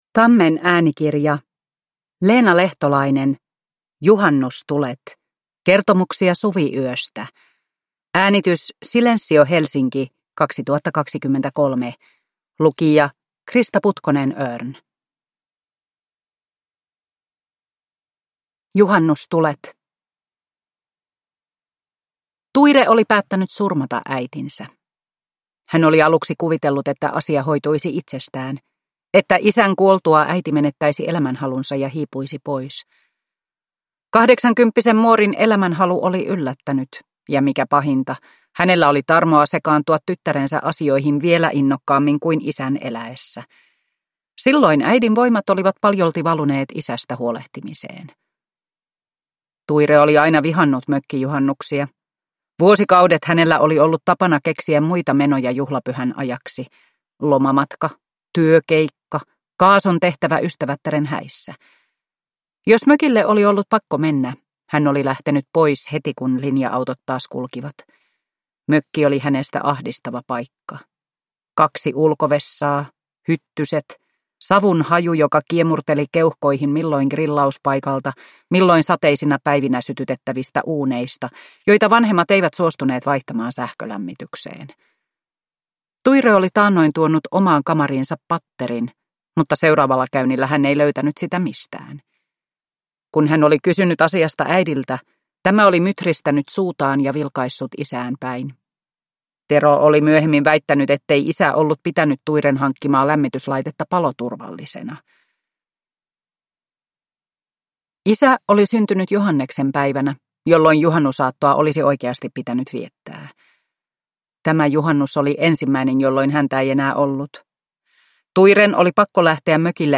Juhannustulet – Ljudbok – Laddas ner